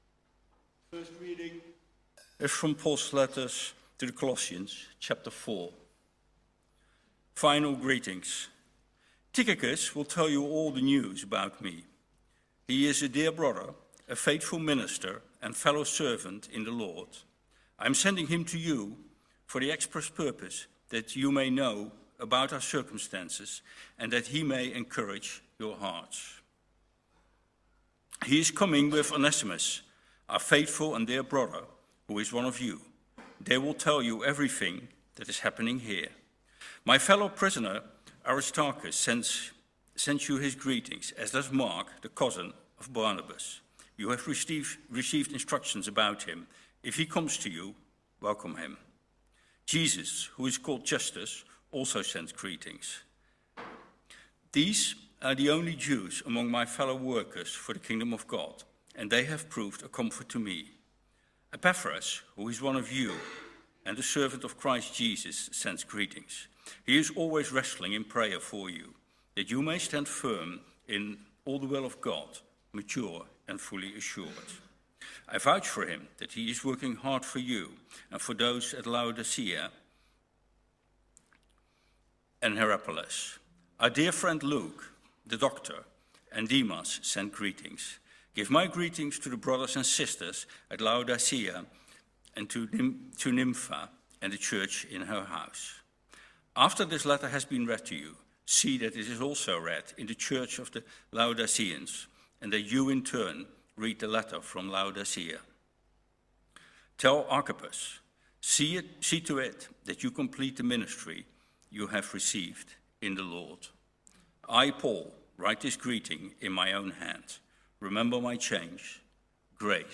This sermon explores what it means to live and serve together: to be faithful in small tasks, to remain steadfast through struggles, to wrestle in prayer like Epaphras, and to persevere until the end like Archippus was urged to do. It also reminds us that failure is never final in Christ, yet finishing well requires endurance and focus.